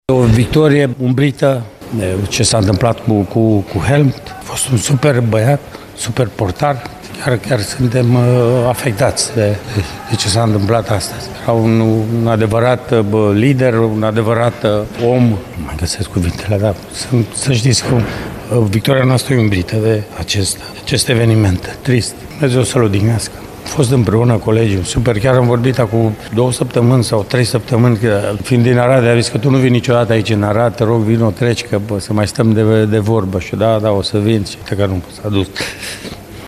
De altfel, antrenorul Mircea Rednic a vorbit în lacrimi la finalul meciului despre această pierdere a fotbalului românesc: